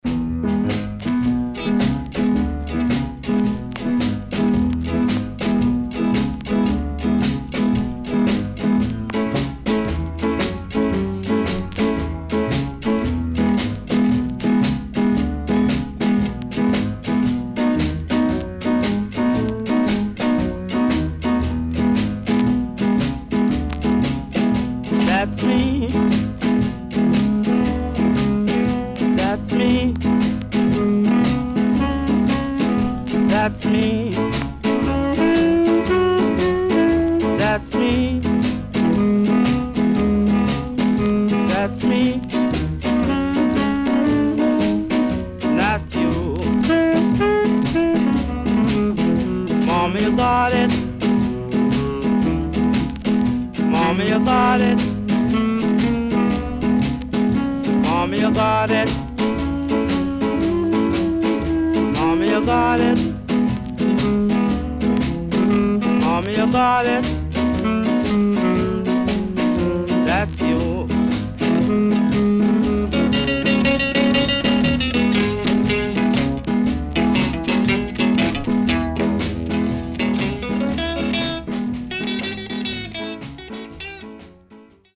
Inst & Early 60's Shuffle Ska
SIDE A:少しチリノイズ、プチノイズ入ります。